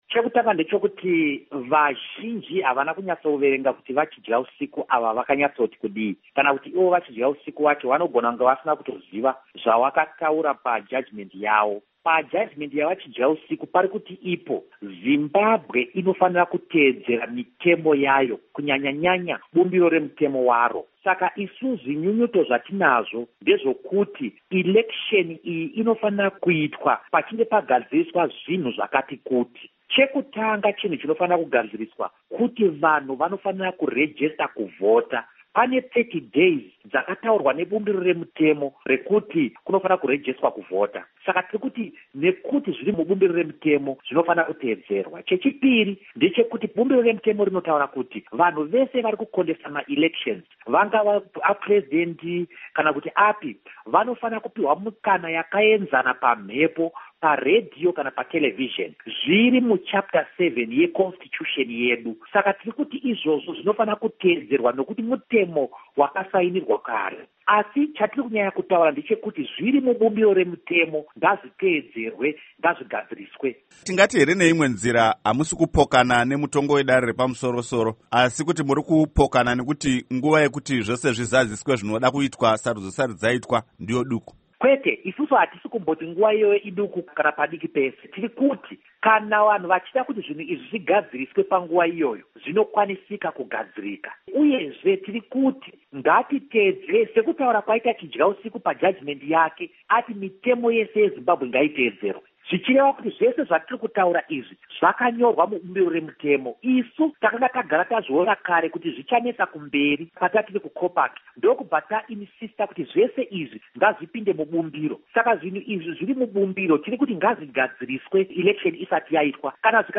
Embed share Hurukuro naVaDouglas Mwonzora by VOA Embed share The code has been copied to your clipboard.